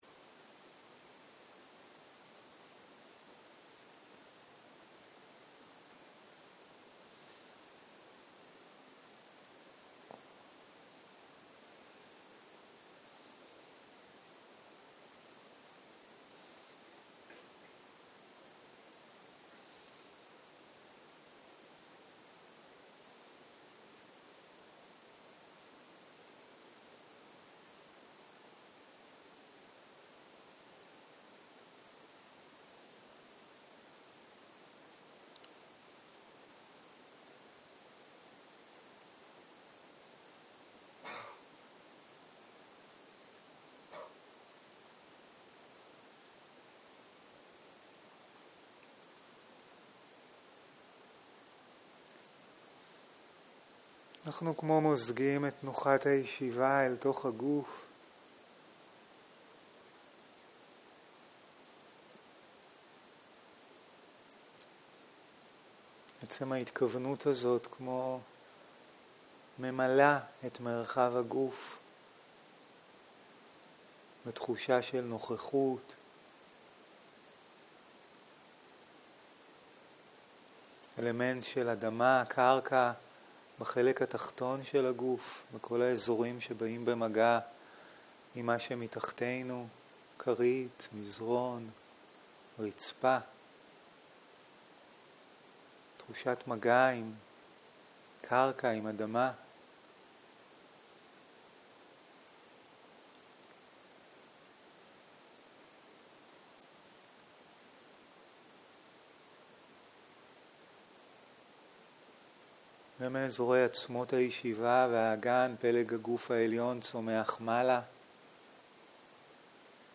צהריים - מדיטציה מונחית - שיחה 11